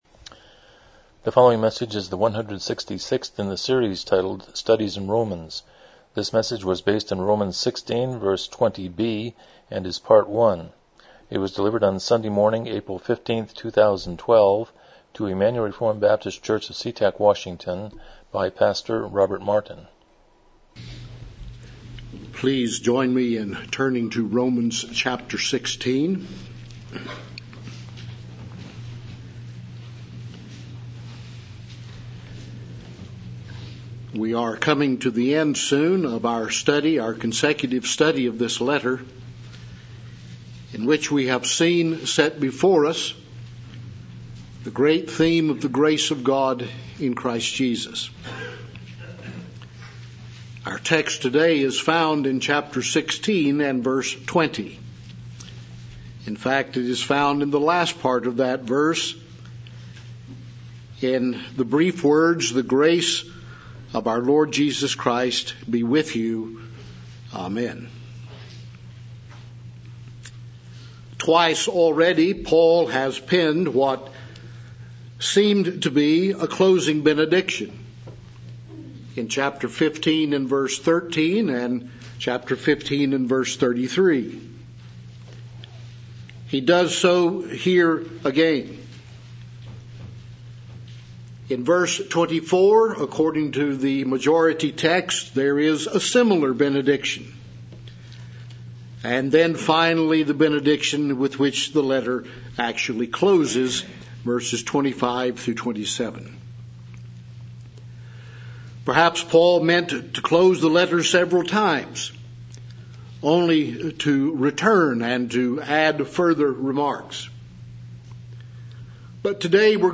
Exposition of Romans Passage: Romans 16:20 Service Type: Morning Worship « 143 Chapter 29.2